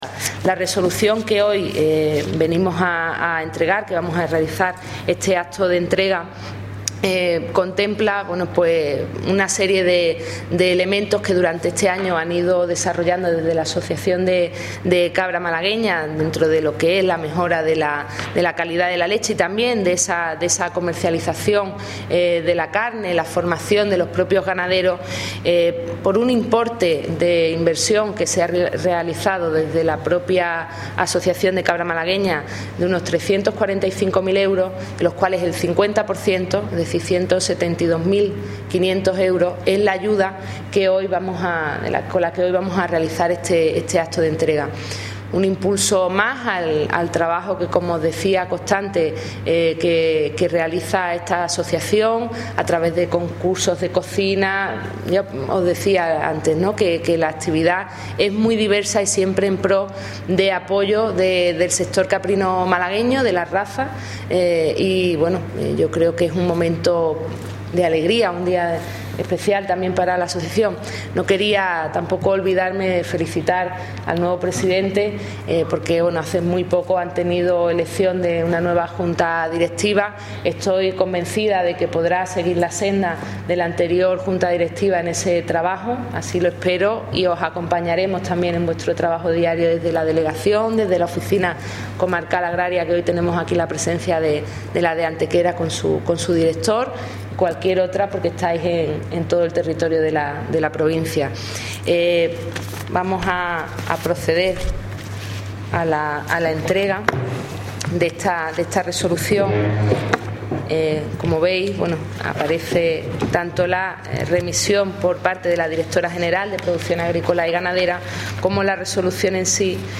Cortes de voz
Audio: delegada de Agricultura   2366.53 kb  Formato:  mp3